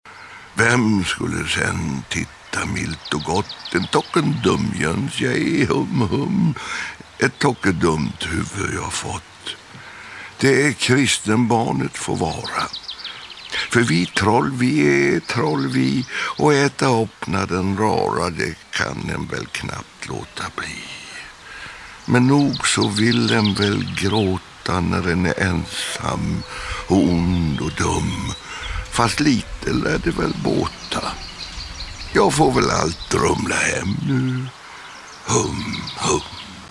L�rdagen den 5 juni i P4 samtalar Sven Wolter med personer fr�n
Fr�n friluftsscenen diskuterar han upps�ttningen av